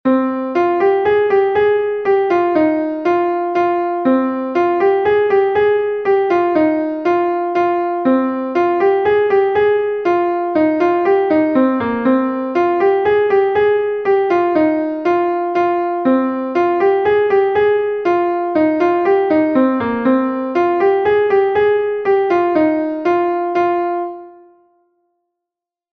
Gavotte de Bretagne